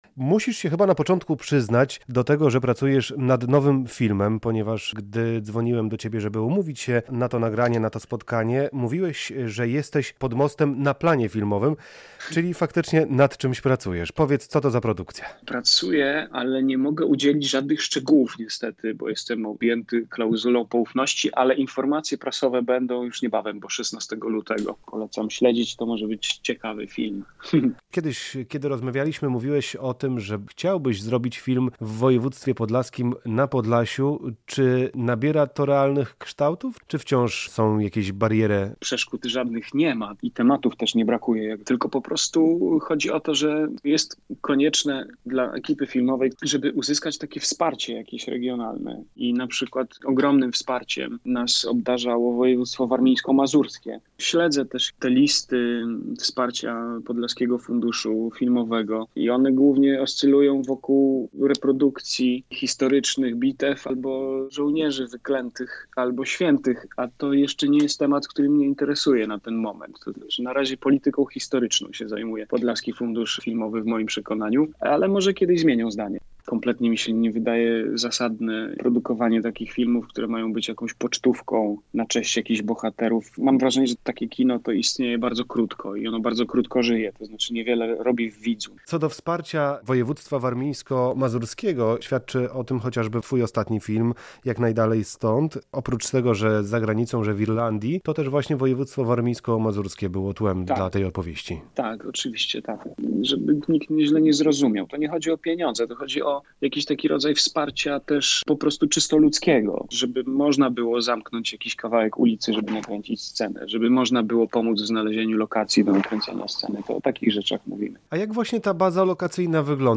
O kinie postpandemicznym opowiada reżyser Piotr Domalewski